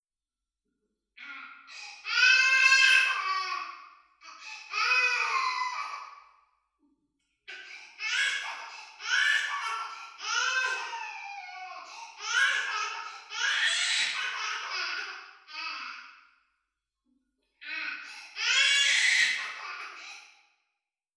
赤ちゃんの泣き声で
響きを比べました。
(5.4×9.1m、高さ2.5mの部屋)
泣き声が響いて、
うるさく感じる